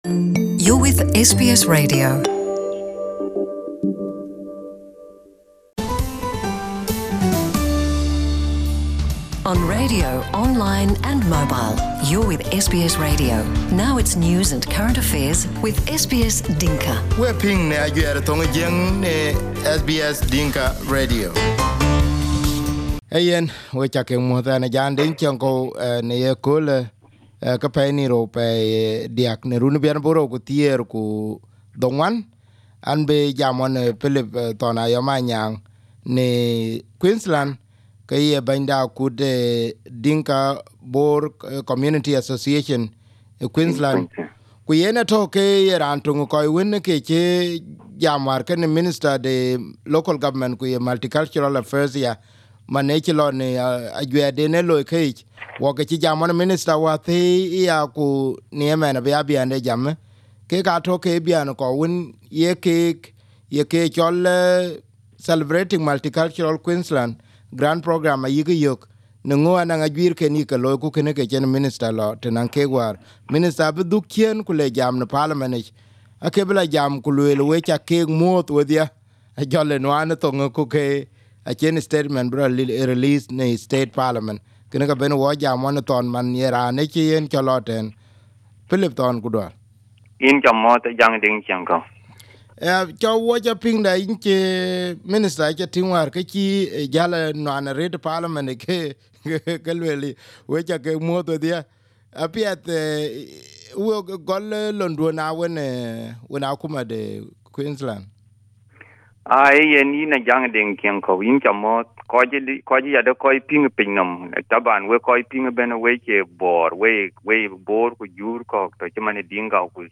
Speaking on SBS Dinka Radio yesterday, Minister appreciates the role being played by the community.